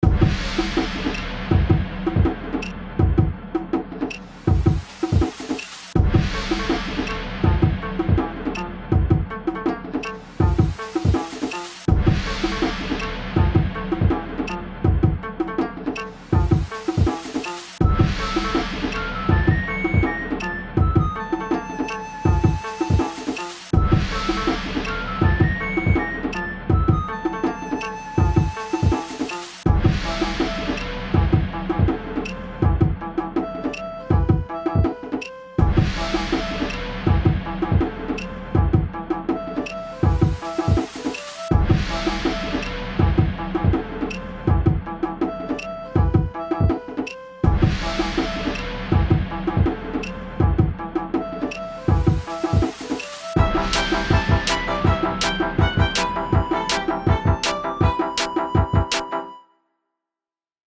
ちょっと進んだボスバトルBGM
ボス戦BGMのイメージが固まってきました。
和風でおどろおどろしくスタートします。サビは一変して明るくしたかったのですが、繋ぎ方がかなり難しくて一旦やめました！
ズンズンなるベースが欲しいですね。